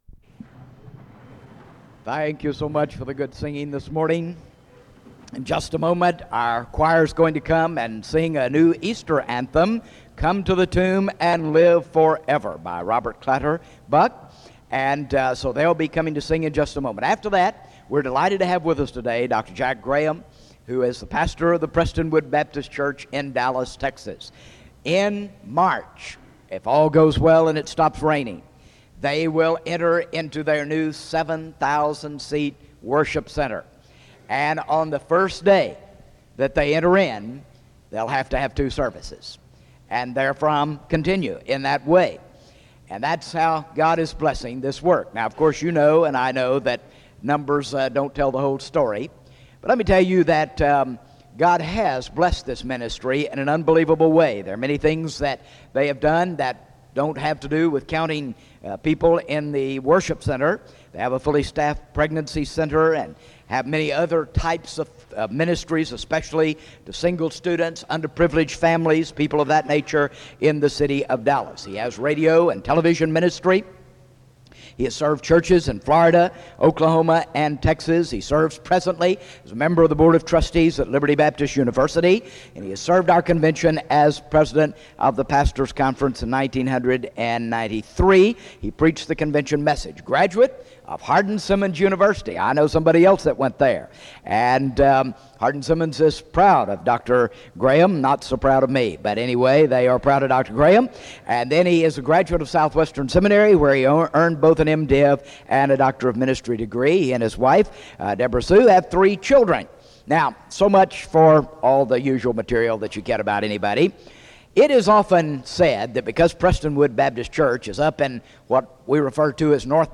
Accueil SEBTS Chapel